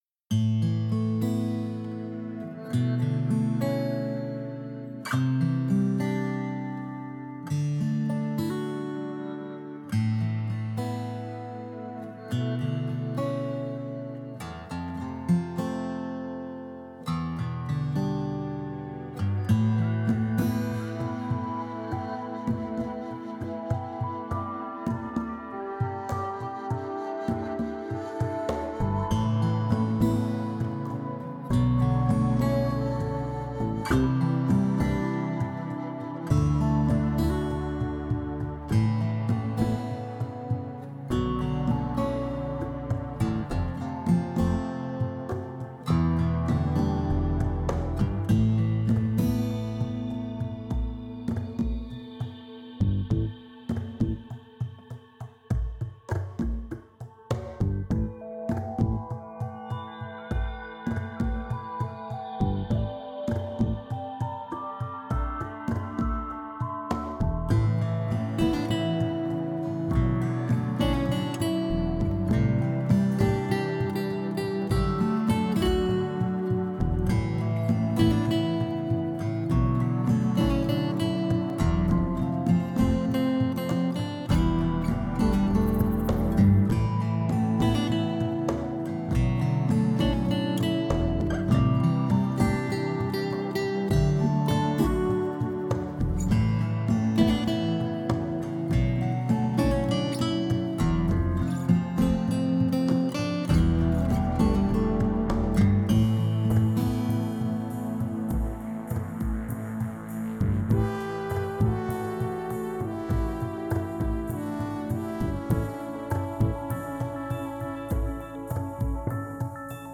Une petite musique tranquille pour ce dimanche.